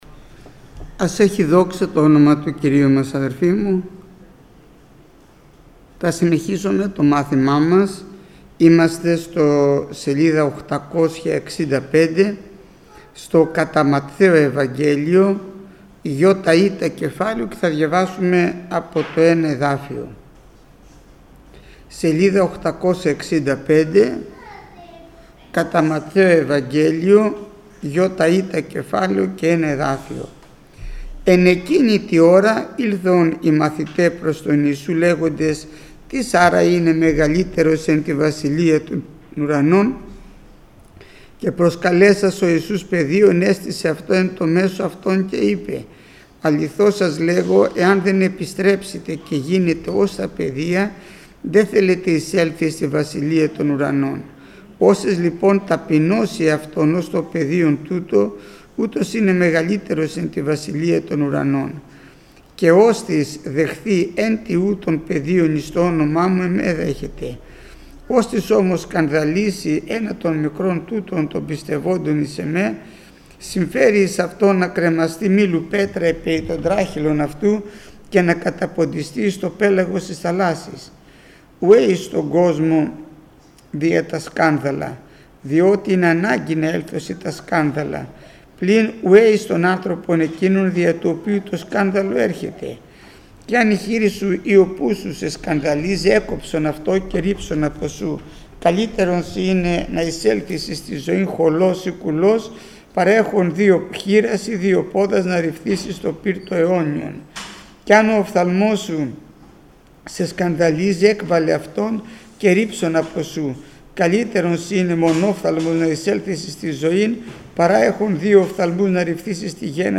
Μάθημα 466ο Γεννηθήτω το θέλημά σου